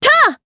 Lorraine-Vox_Attack3_jp.wav